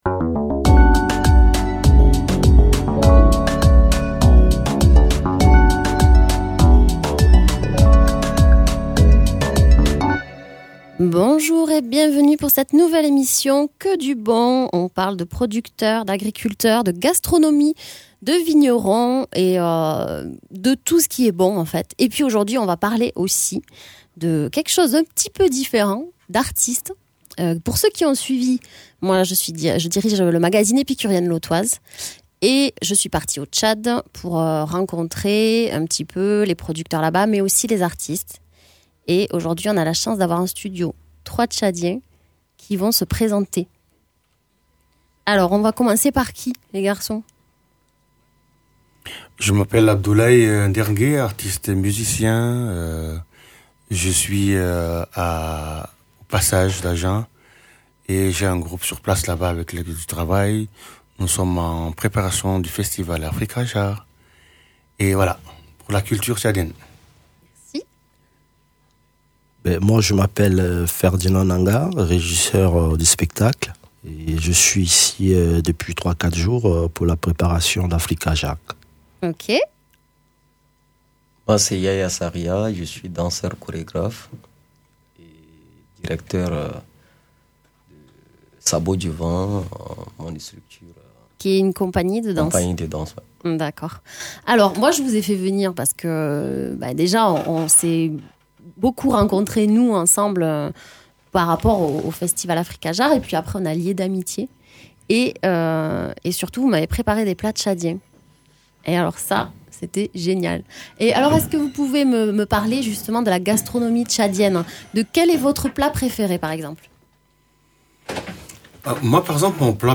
artistes tchadiens.